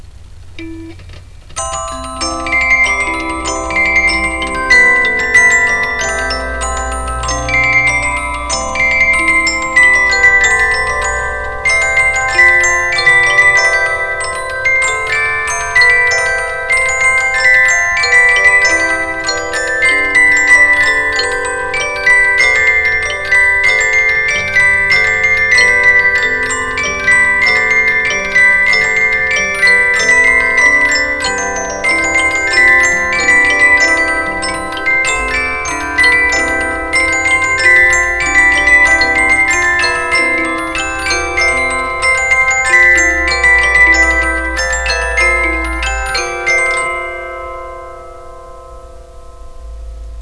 Criterion Music Box
I have a Criterion Disc Music Box.